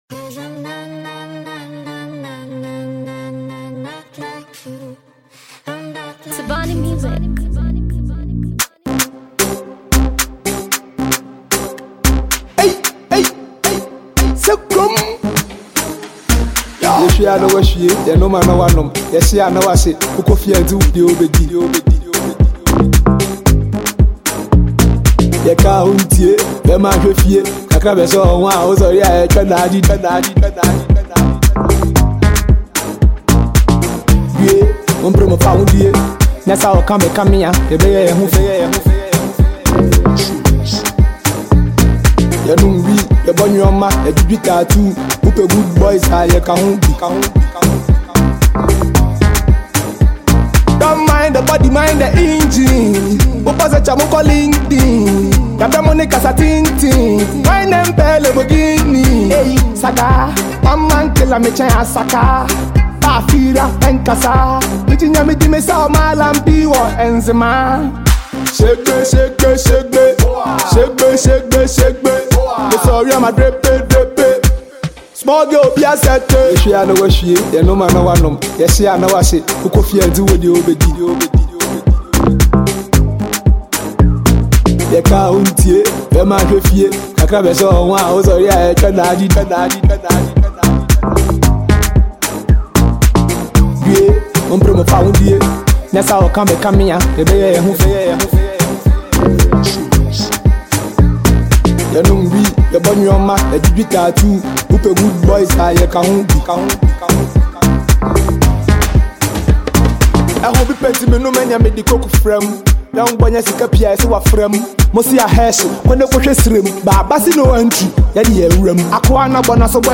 Hiphop Afrobeat